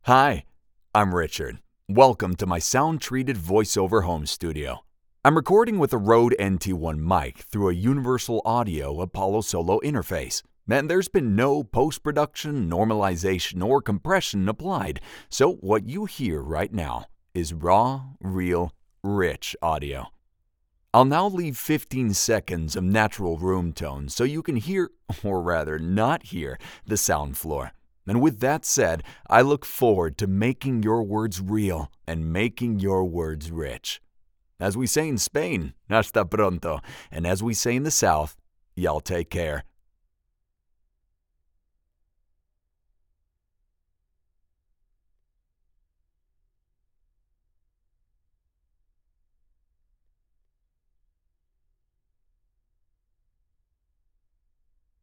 Commercial
Male
Approachable, Assured, Authoritative, Confident, Conversational, Deep, Energetic, Engaging, Gravitas, Reassuring, Smooth, Versatile, Warm, Corporate, Natural
General American (native) Spanish (native) US Southern (native) New Orleans RP Cockney
My rich, low baritone voice has a General American accent in English and a Castilian accent in Spanish, and spans an age range from late 20s to late 30s. Like a bold (yes, Spanish) red wine, it’s full of smooth, velvety warmth, and a character that leaves a lasting impression.
Microphone: Røde NT1 | Shure SM7B